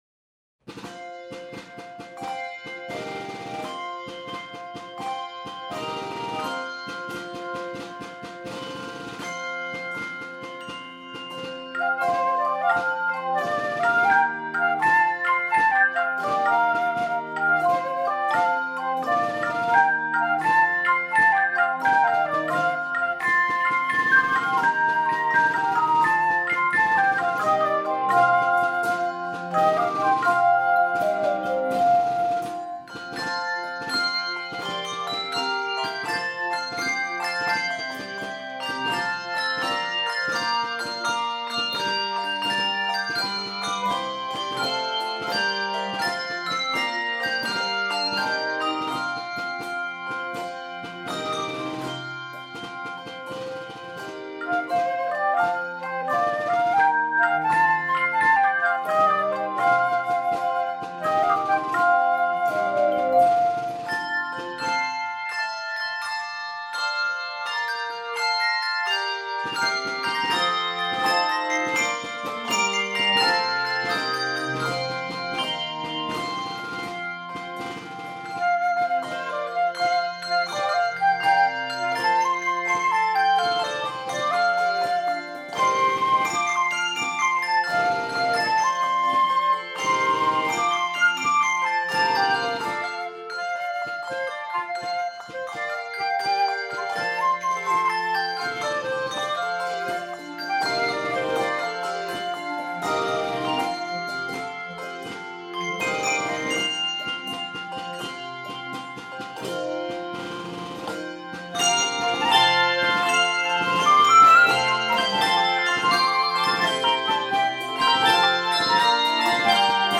This energetic hymn